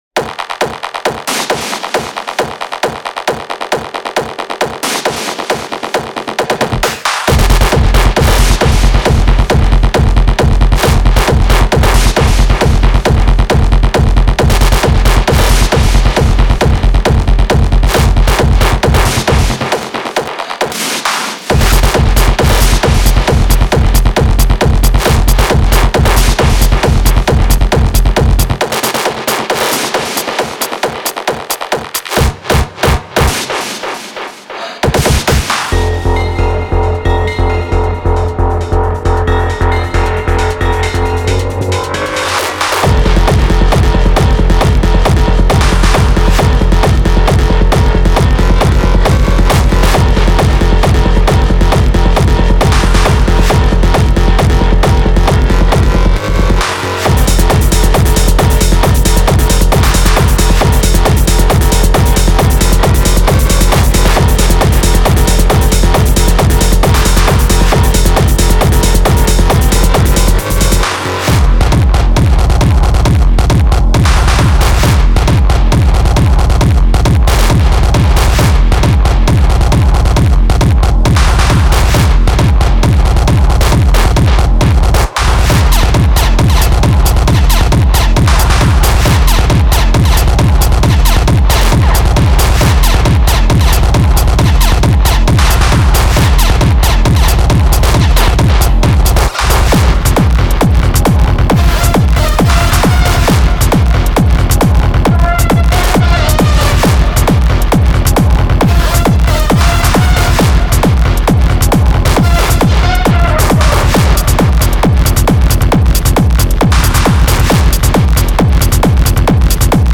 Genre:Industrial Techno
デモサウンドはコチラ↓
45 Drum Loops
22 Rave Synths